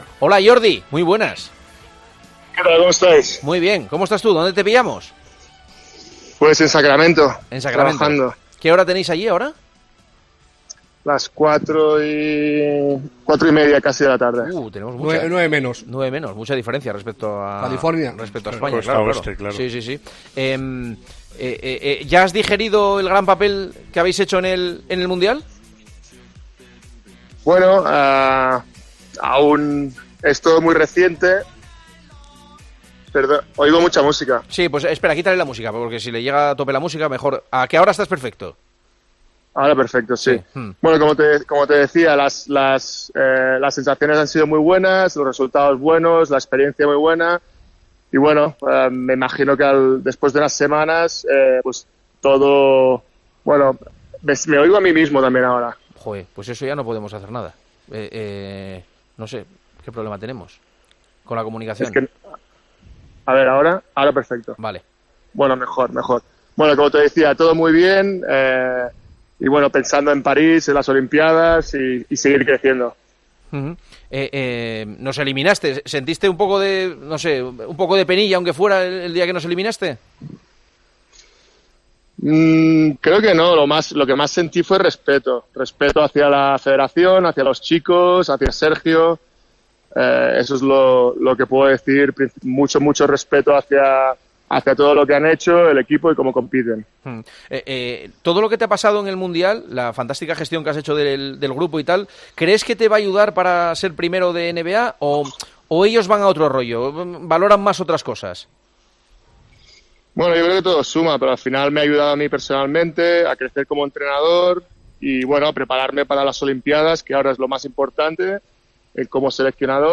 El seleccionador de baloncesto de la selección de Canadá habla en El Partidazo de COPE tras la medalla de bronce que lograron en el torneo.